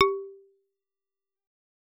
content/hifi-public/sounds/Xylophone/G2.L.wav at main